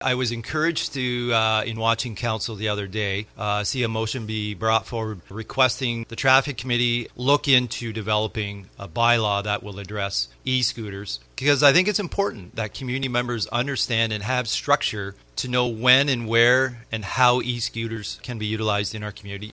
Chief Campbell laid the law out there regarding these scooters for myFM.